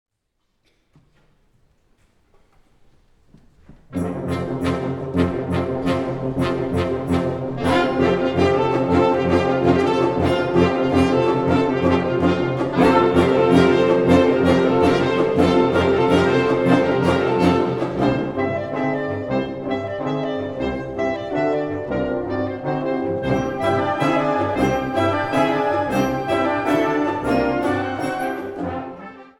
Categorie Harmonie/Fanfare/Brass-orkest
Bezetting Ha (harmonieorkest)